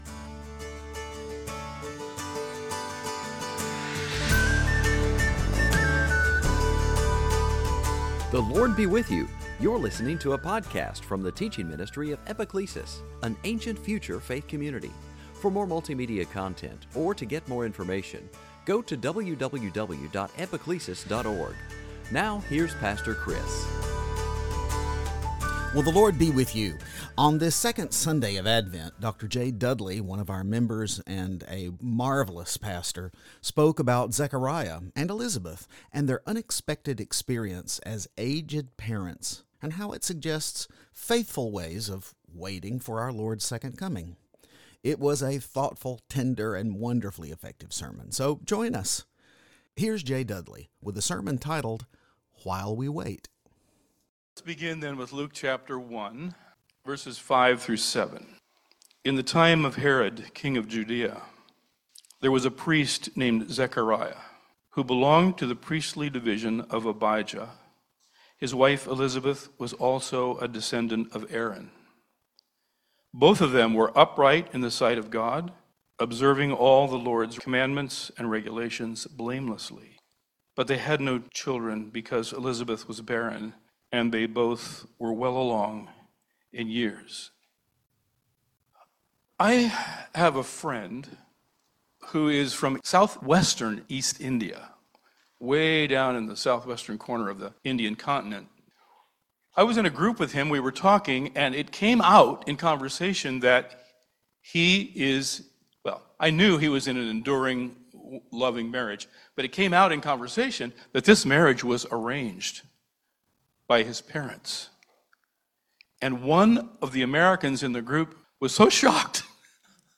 It was a thoughtful, tender, and wonderfully effective sermon.